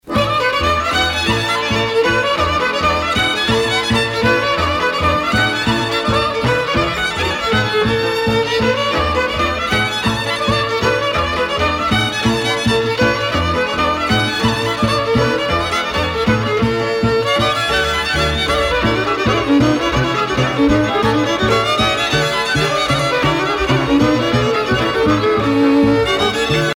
danse : învîrtita (Roumanie)
Pièce musicale éditée